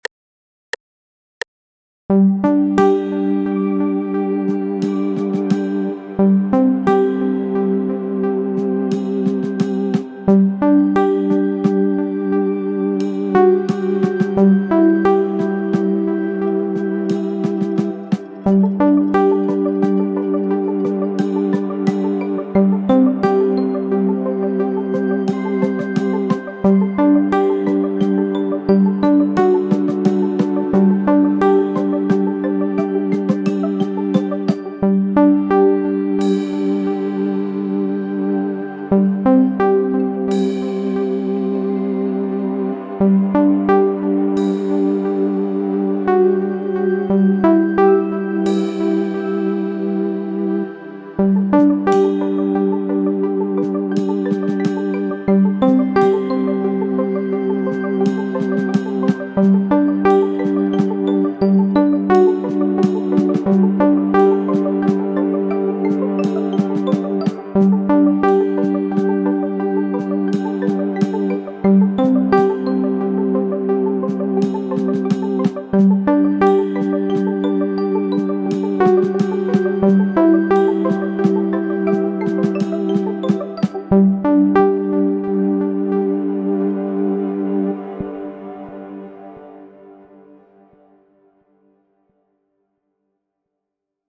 Tehtävän alta löydät samat kappaleet ilman melodioita.
G-duuri
Huomaa 3/4 -tahtiosoitus.